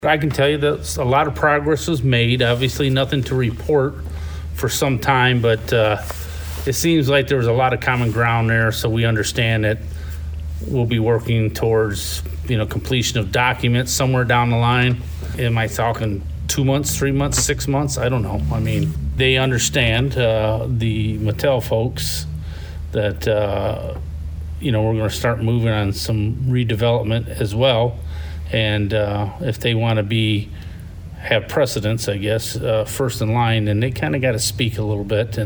Bradley Mayor Mike Watson said progress was made during his trip last week to Texas to talk with Mattel officials about expanding their footprint in Bradley beyond the waterpark set to break ground later this year. Mayor Watson gave his comments about it after Monday night’s board meeting.